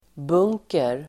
Uttal: [b'ung:ker]